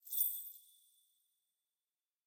03_鬼差脚步_1.ogg